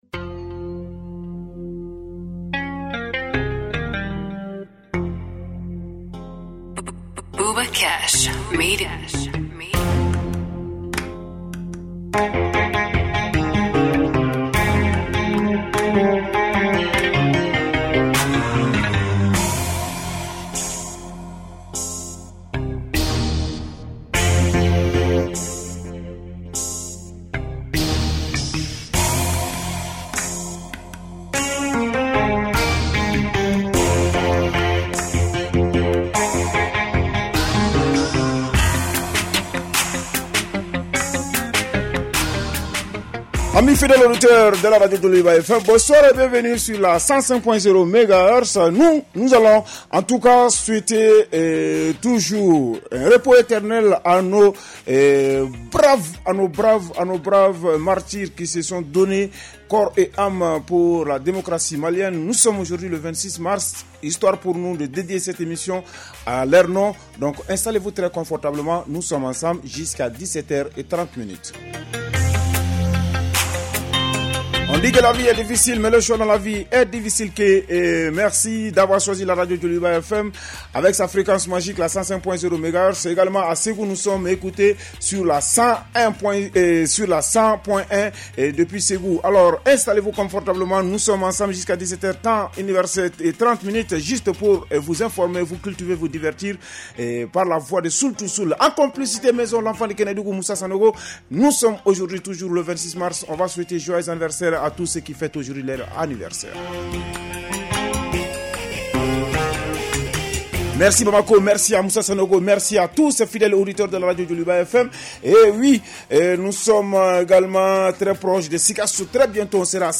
Un programme 100 % dédié à la scène rap et hip-hop du Mali avec des interviews exclusives, des freestyles et toute l’actualité croustillante de vos rappeurs préférés.